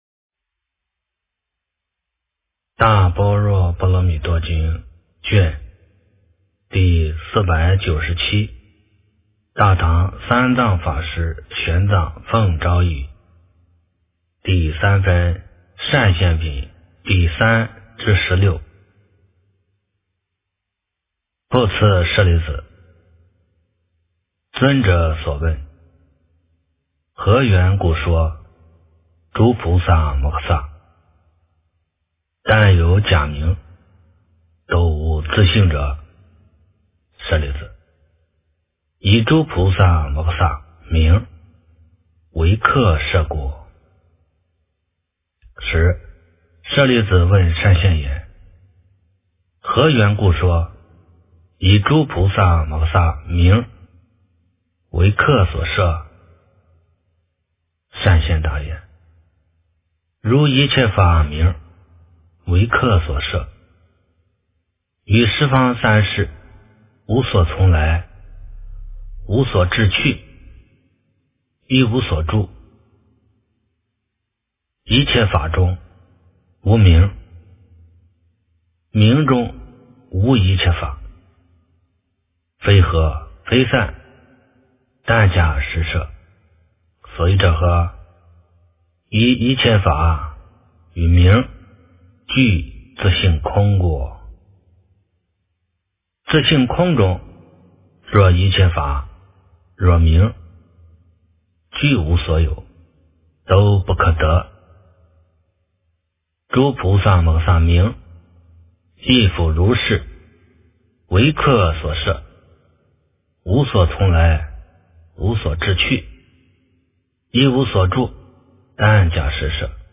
大般若波罗蜜多经第497卷 - 诵经 - 云佛论坛